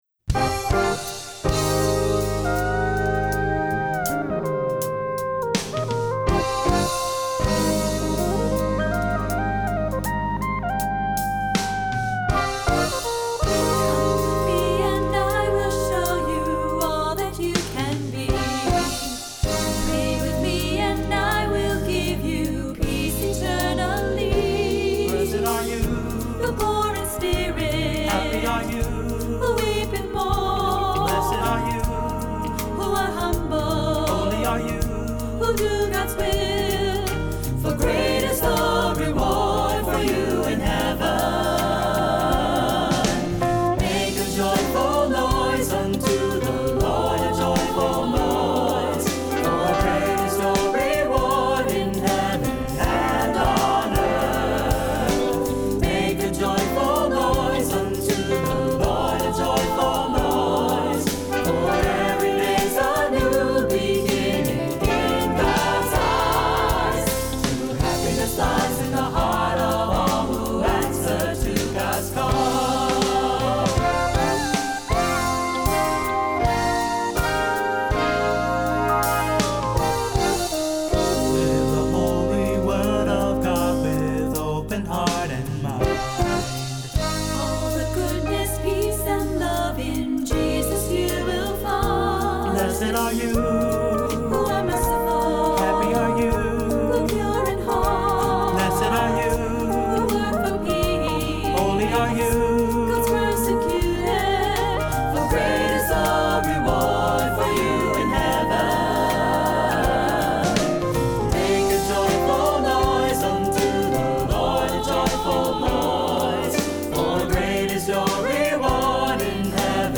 Accompaniment:      Piano, Synthesizer
Music Category:      Christian